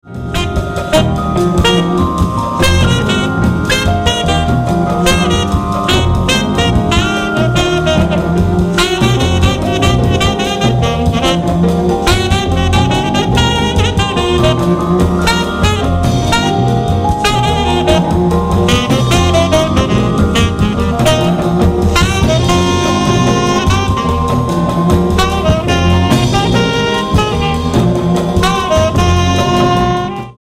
Monster spiritual Jazz reissue.